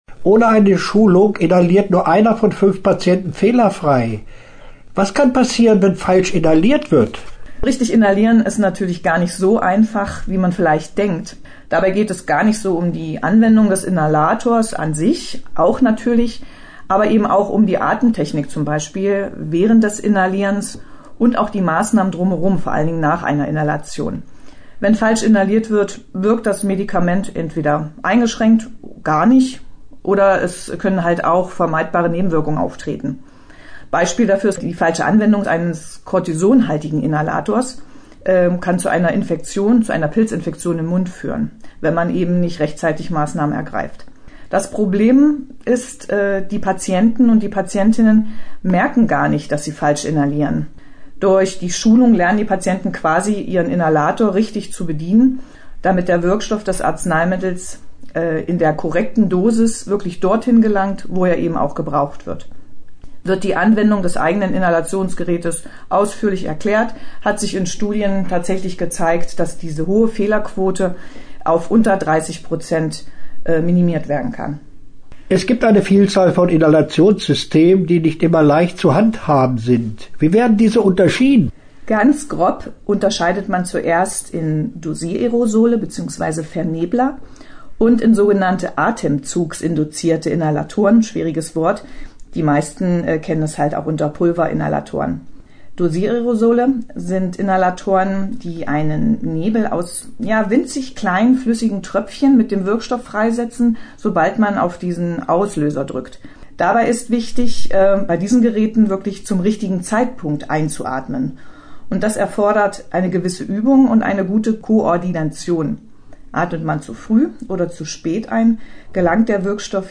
Interview-Inhalations-Schulung.mp3